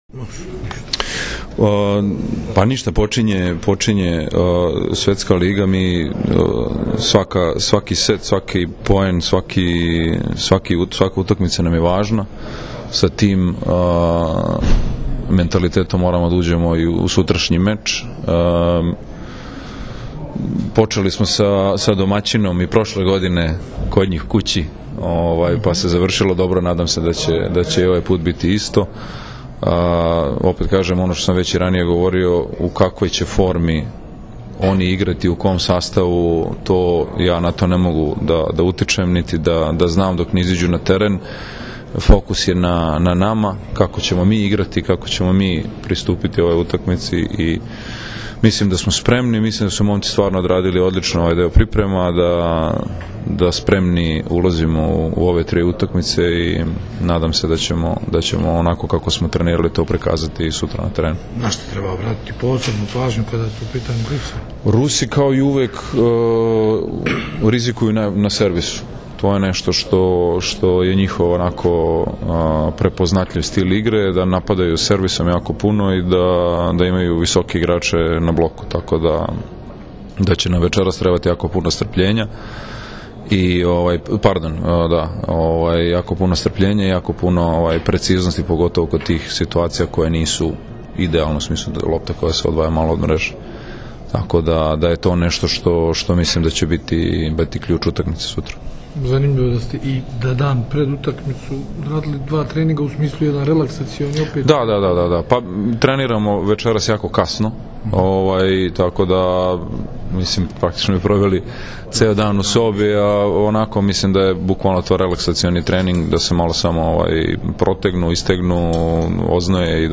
Treneri na konferenciji za novinare – Srbija u petak (19,10) protiv Rusije